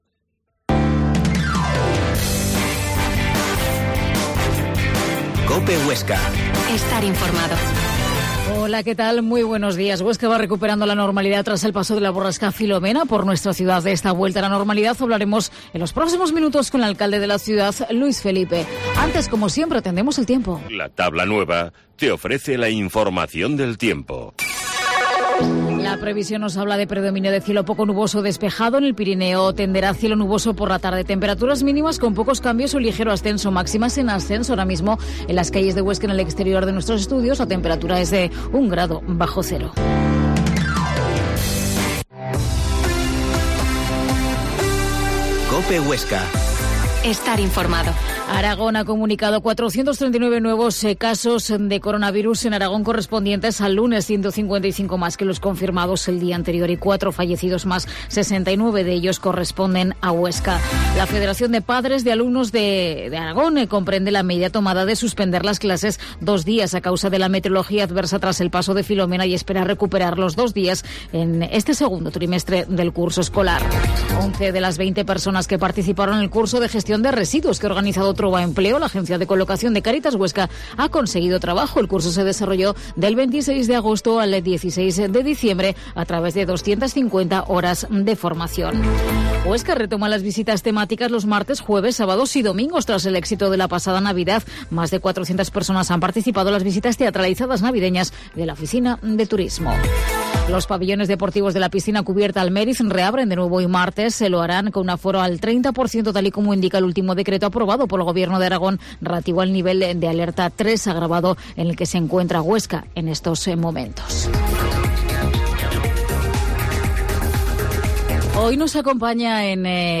Herrera en COPE Huesca 12.50h Entrevista al alcalde de Huesca, Luis Felipe